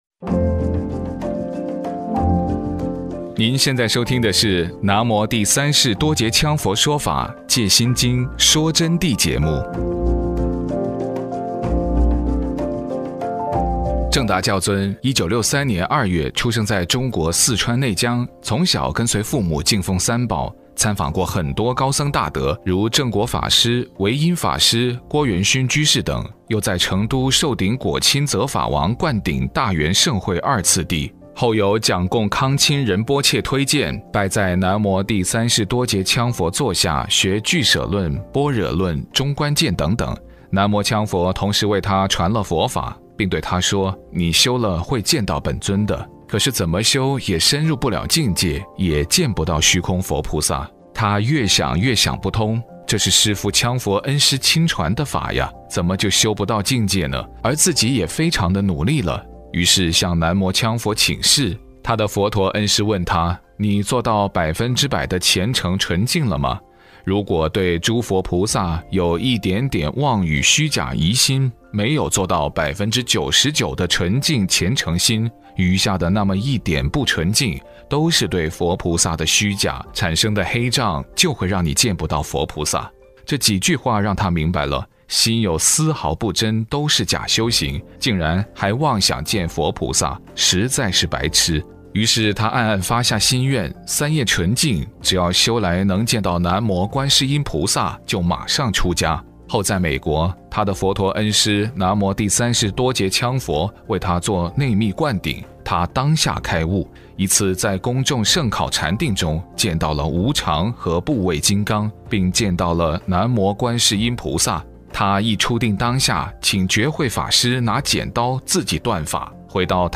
佛弟子访谈（五十八）末法时期佛教的现況，开悟就是成就解脱吗？学到真正的佛法有多重要 – 福慧网